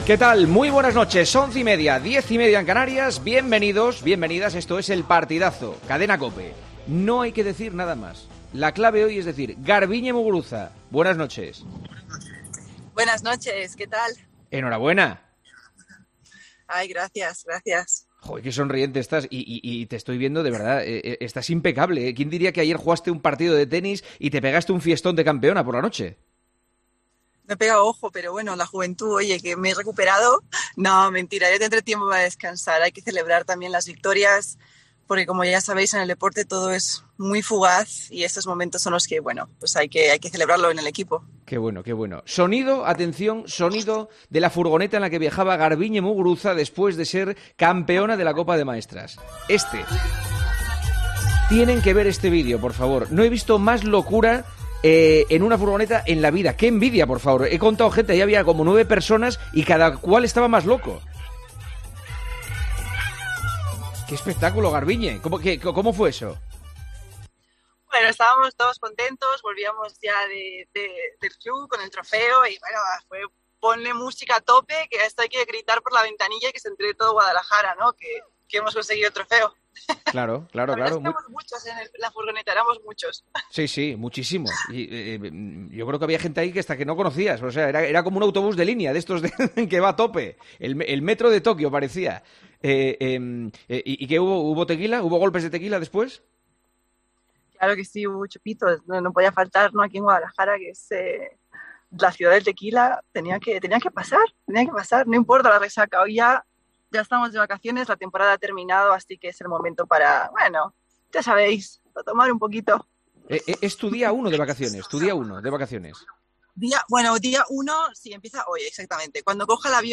Juanma Castaño entrevista en El Partidazo de COPE a Garbiñe Muguruza después de que la tenista española hiciera historia ganando el Torneo de Maestras.
AUDIO - ENTREVISTA A MUGURUZA